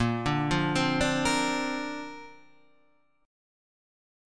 A#add9 Chord